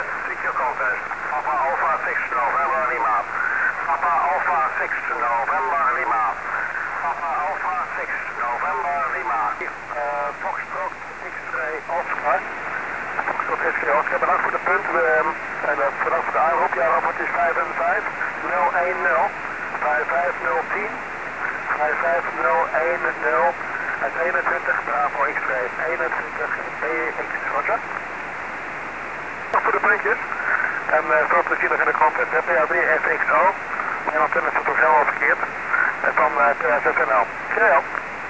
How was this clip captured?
Some audio recordings of my first 23cm qso's on 2 and 3 oktober 2010, at the UHF-SHF contest in europe: